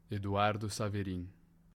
Eduardo Luiz Saverin (/ˈsævərɪn/ SAV-ər-in, Brazilian Portuguese: [eduˈaʁdu luˈis saveˈɾĩ]
Pt-br-Eduardo-Saverin.ogg.mp3